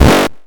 Soundspack 05 (8bit SFX 01-...
Damage_02.mp3